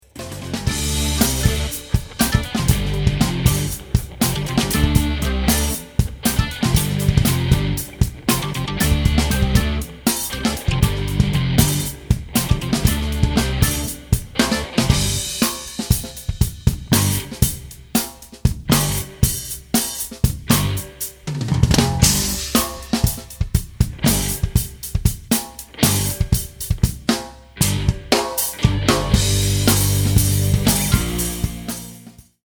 A play-along track in the style of Brazilian, fusion.